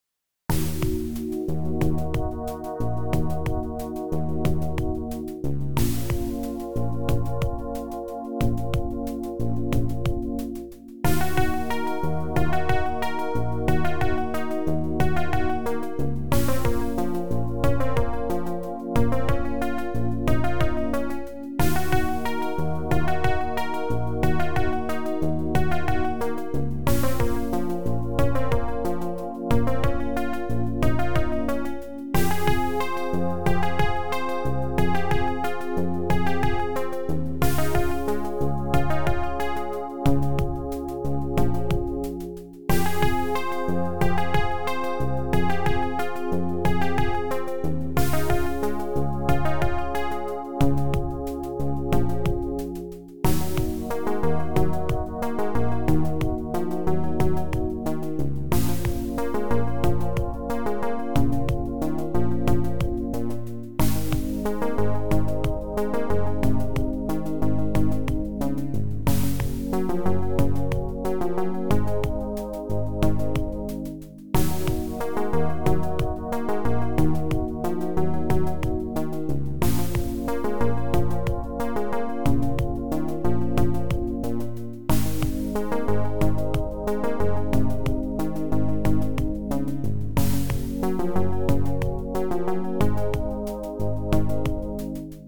HSC AdLib Composer